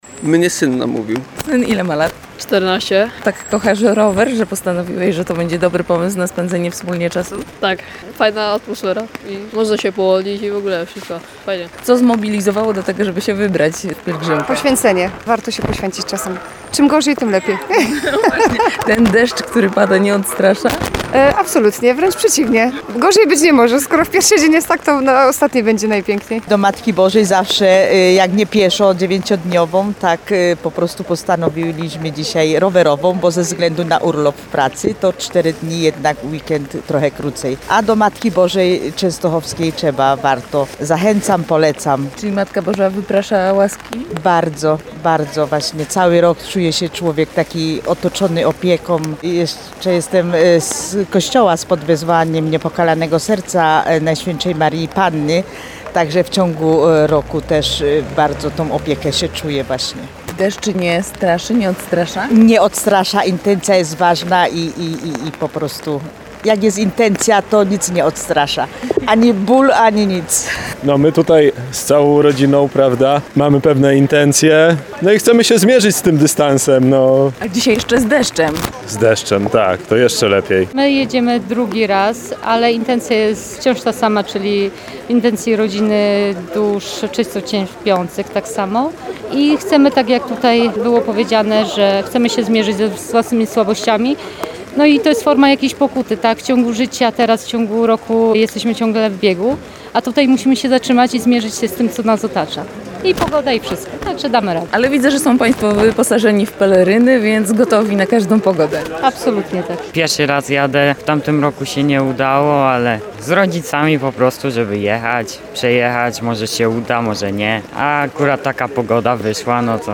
Spod bazyliki św. Małgorzaty w Nowym Sączu Rowerowa Pielgrzymka Tarnowska wyjechała na szlak.